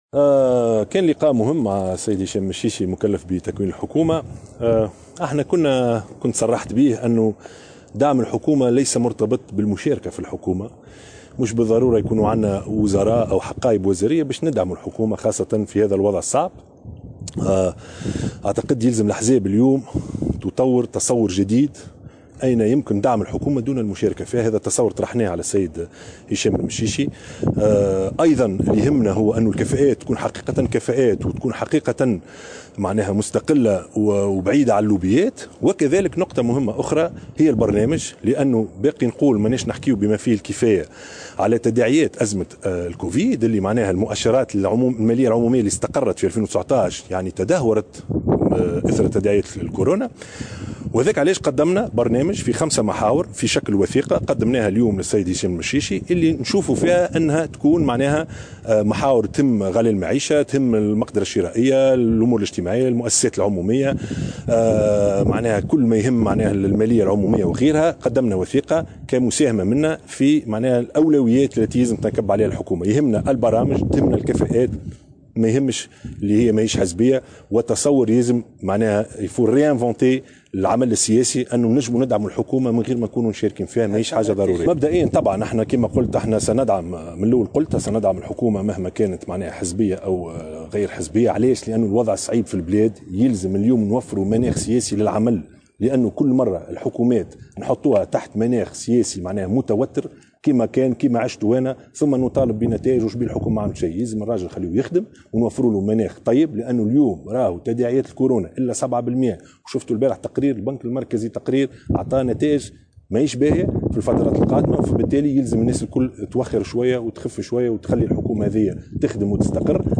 وأفاد الشاهد، في تصريح عقب لقاء جمعه، ورئيس كتلة الحزب مصطفى بن أحمد، بالمكلف بتكوين حكومة هشام المشيشي بدار الضيافة بقرطاج، بأنه قدم تصورا خلال اللقاء يتعلق بدعم الحكومة دون المشاركة فيها، وقال إنه قدم أيضا "وثيقة برنامج" من 5 محاور تتضمن أولويات يرى أنه على الحكومة المقبلة ان تنكب عليها، ومن بين أهدافها مقاومة غلاء المعيشة وإصلاح وضعية المؤسسات العمومية والمالية العمومية .